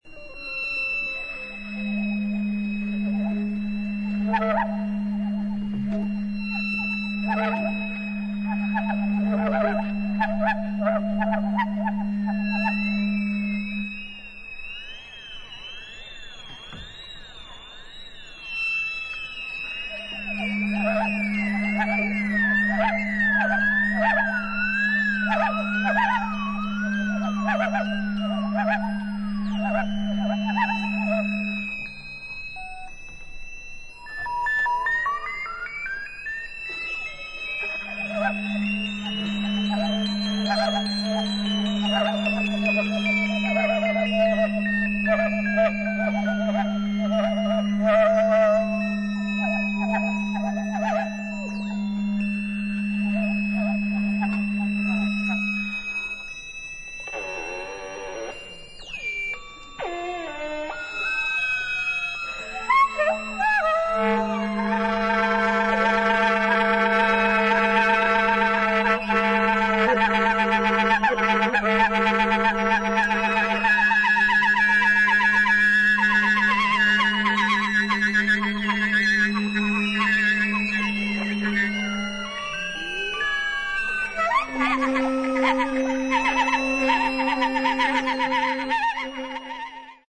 1977年12月にイタリア/ピストイアで行ったライヴを収録したCDアルバム